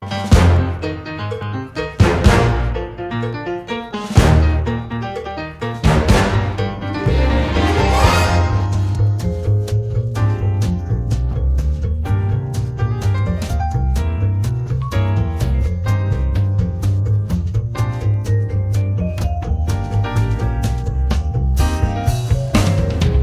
tmpd6_mx2pjday-of-sun-clip_instrum.wav